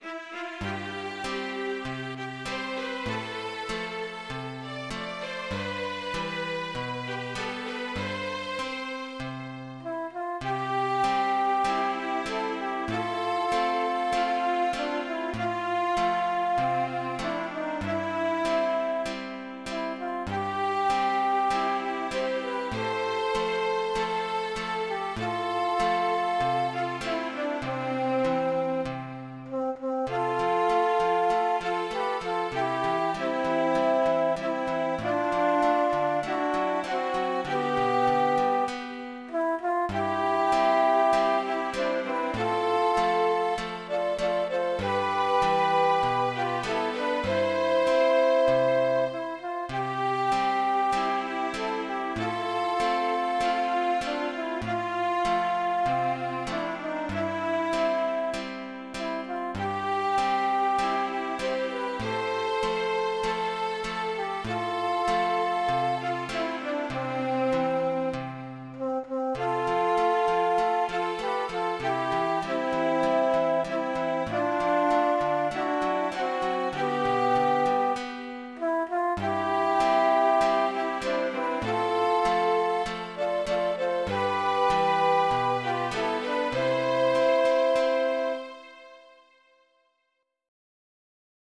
シュトライヒ・プサルター（Streichpsalter)は弦を馬の尻尾の弓で弾く楽器で、
私たちのサークルでは、楽器を自分達で作って演奏を楽しんでいます。
2025年  11月27日  　御野場西町内会　 ♫ 四季の歌(midi)
(mp3)　 訪問演奏